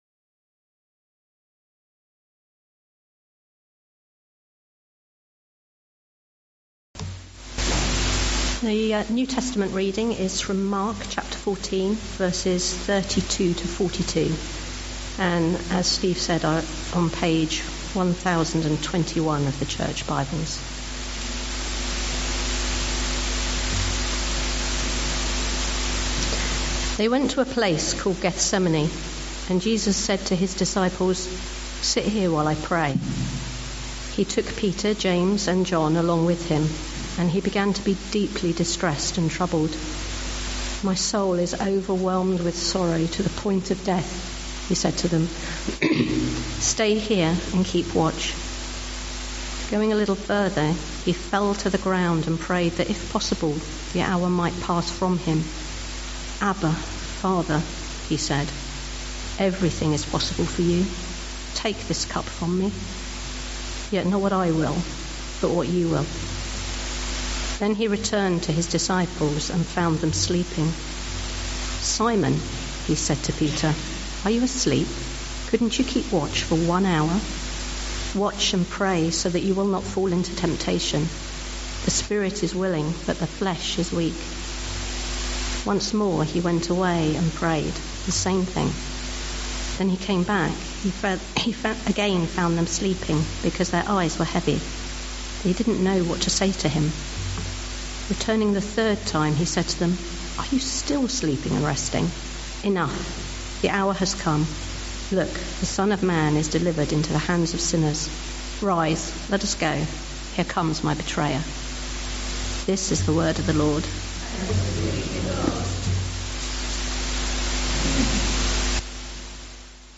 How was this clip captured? Dagenham Parish Church Morning Service